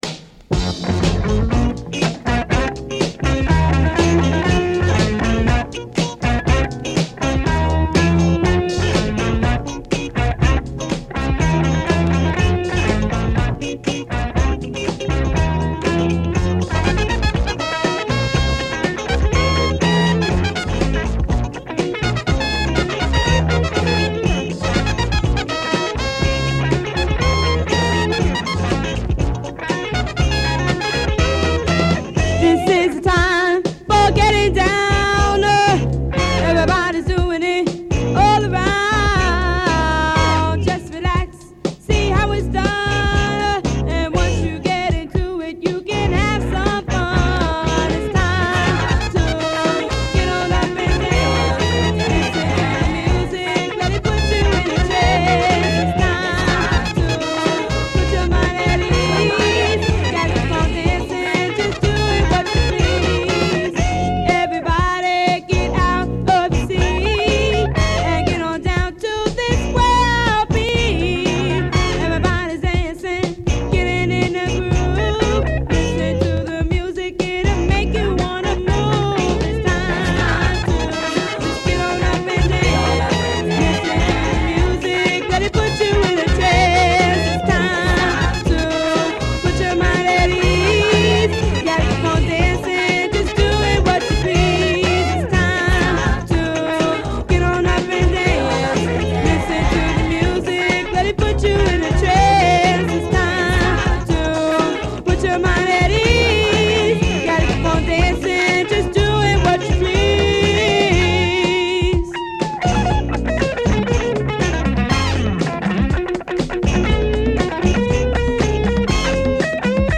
The obscure boogie sound of the early 80s.